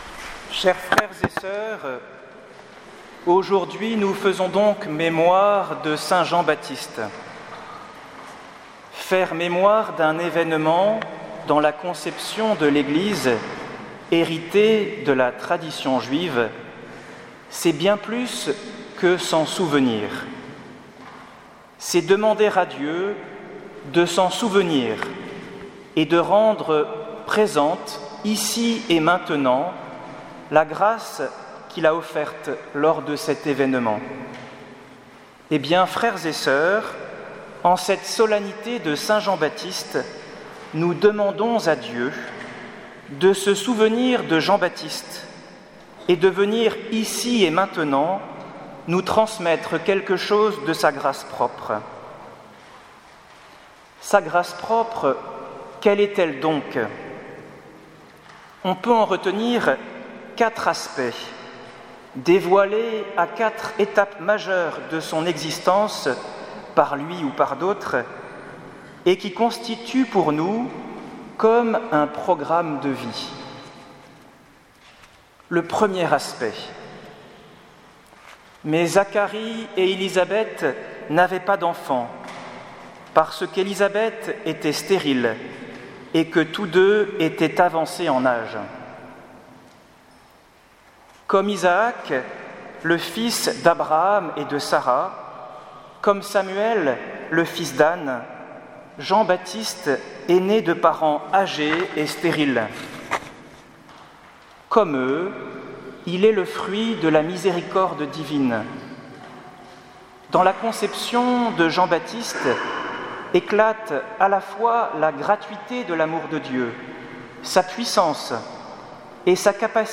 Homélie de la fête de la Nativité de Saint Jean-Baptiste